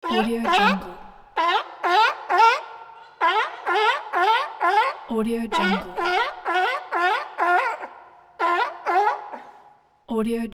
Sea Lion Barking Téléchargement d'Effet Sonore
Sea Lion Barking Bouton sonore